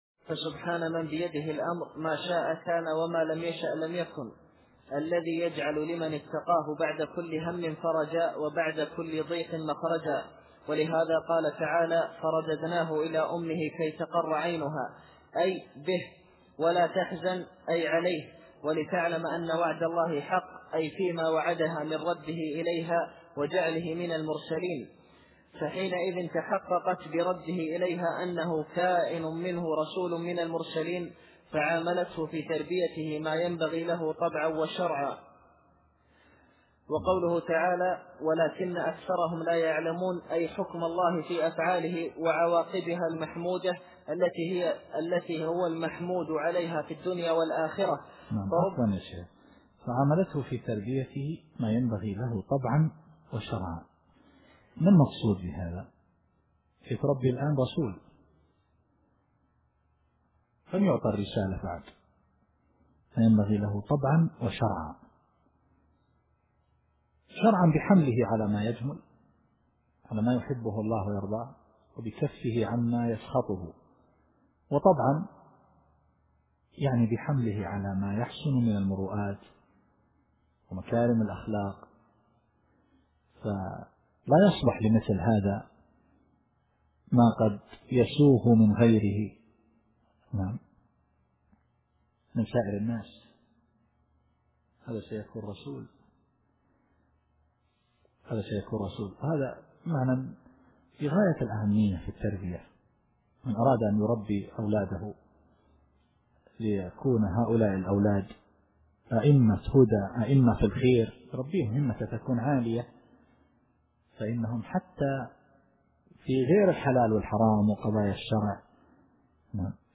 التفسير الصوتي [القصص / 13]